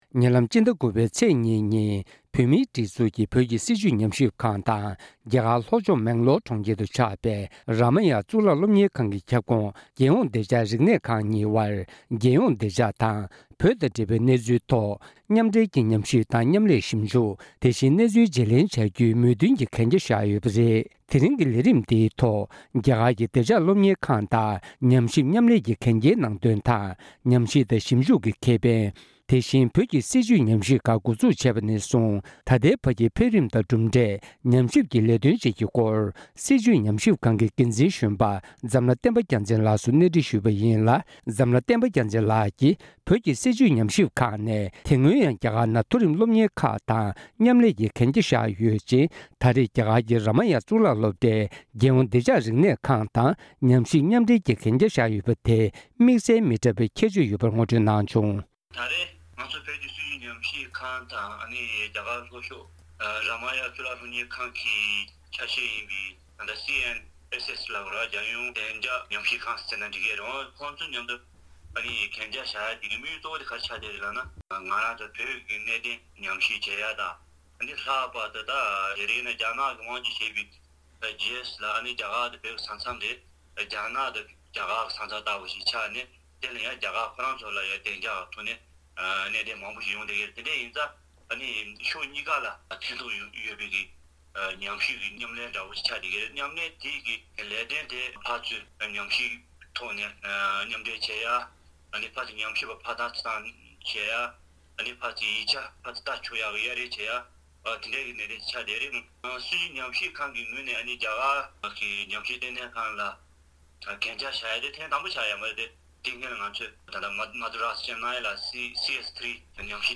གནས་འདྲི་ཕྱོགས་བསྒྲིགས་ཞུས་པ་གསན་རོགས་གནང་།